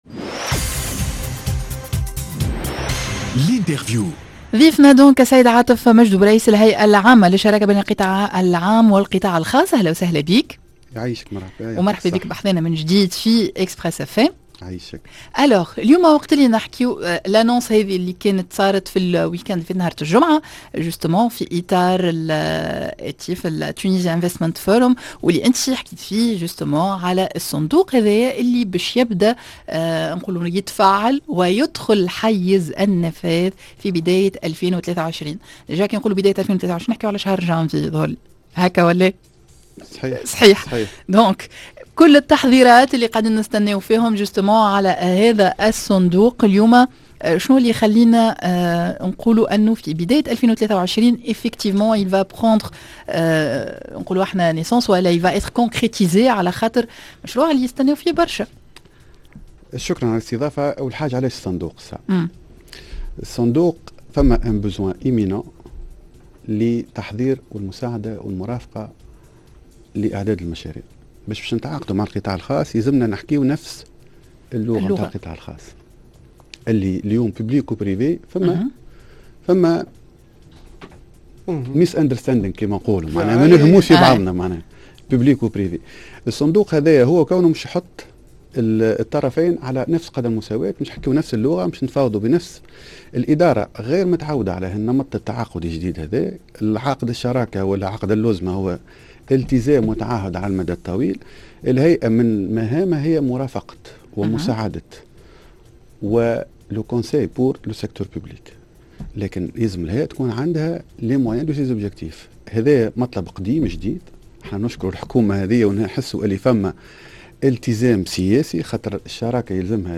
L'interview: صندوق دعم الشراكة بين القطاعين العام والخاص يدخل حيز التنفيذ في 2023 / عاطف مجدوب رئيس الهيئة العامة للشراكة بين القطاع العام والقطاع الخاص